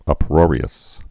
(ŭp-rôrē-əs)